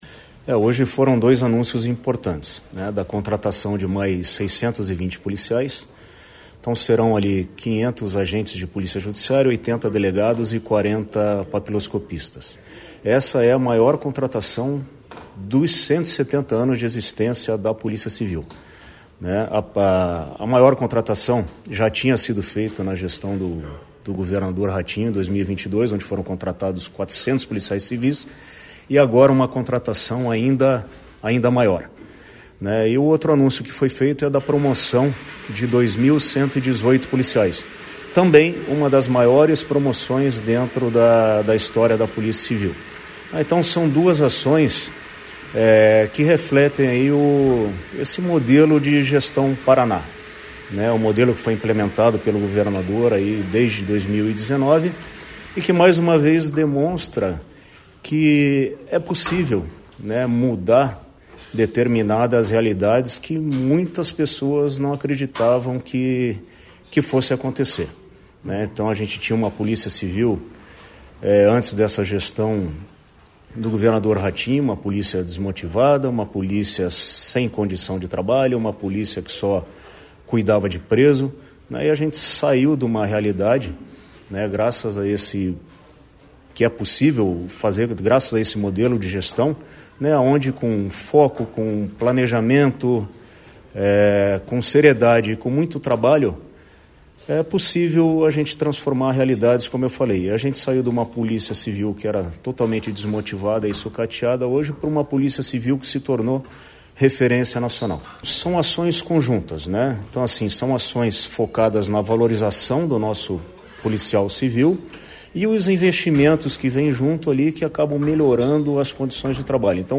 Sonora do delegado-geral da Polícia Civil, Silvio Rockembach, sobre a contratação de 620 novos profissionais para a PCPR